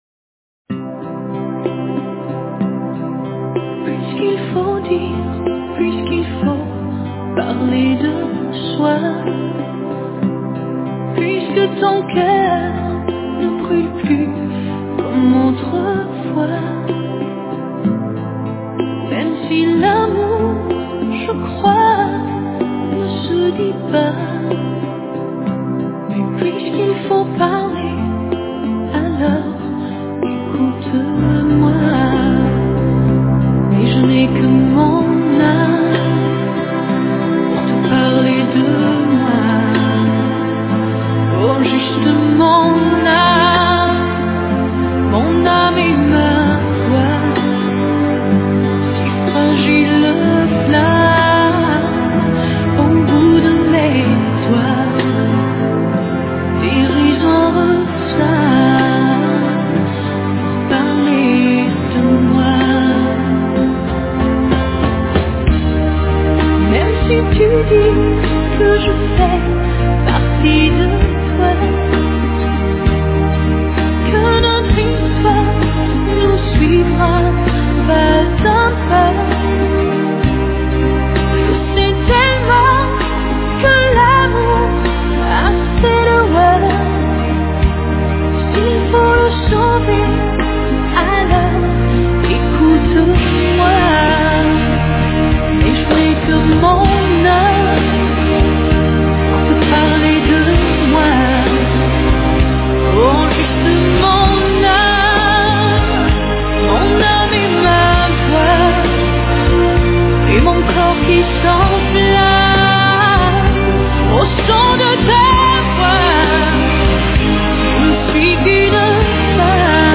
no final tem um Link para Abrir a Música que é Cantada.